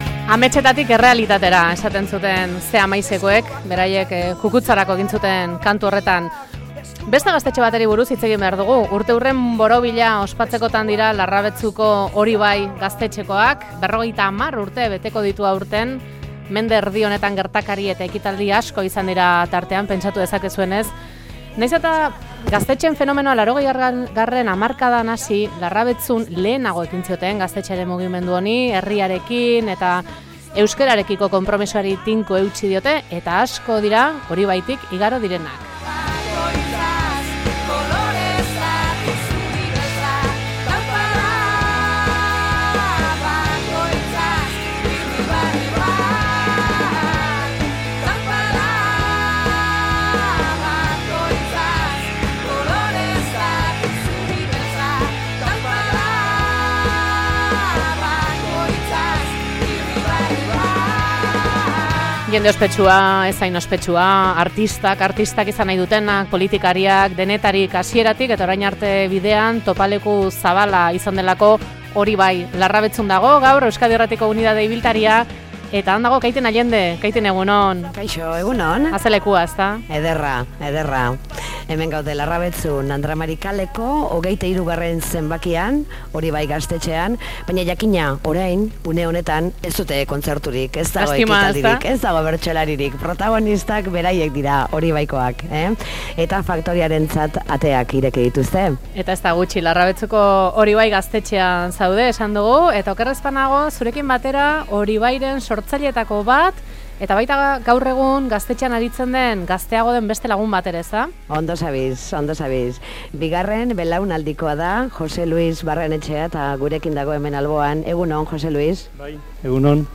Larrabetzuko Hori bai gaztetxeak, Euskal Herriko lehenak, 50 urte betetzen dituela-eta bertan izan da Euskadi irratiaren unitate ibiltaria.